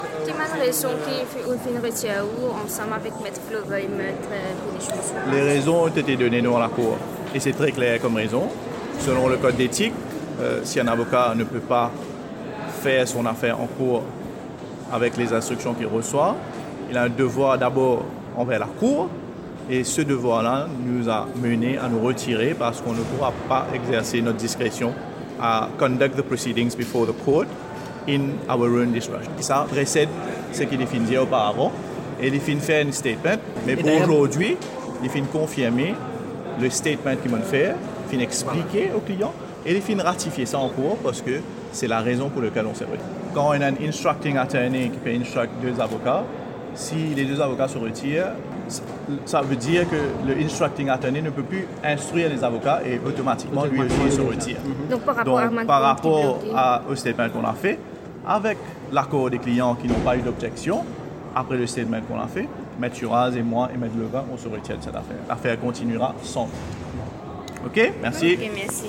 en cour de Port-Louis